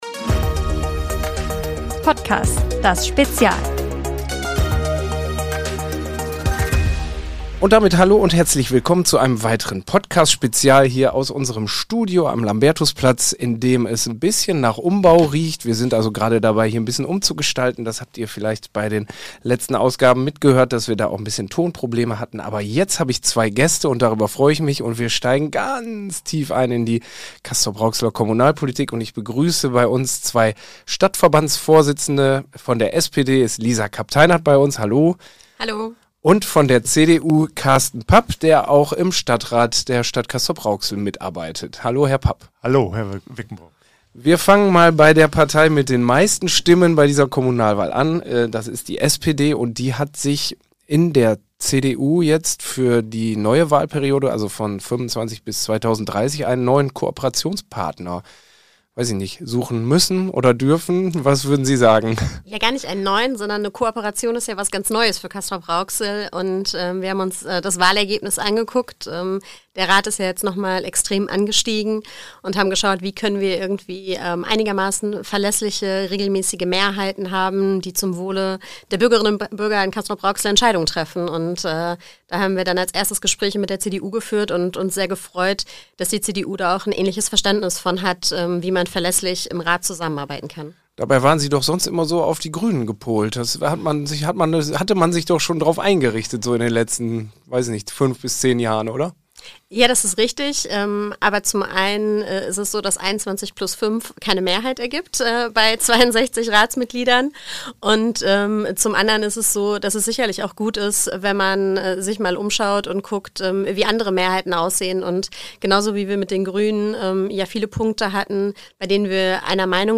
Wir haben sie in unser Studio eingeladen und sie 40 Minuten lang ausgefragt.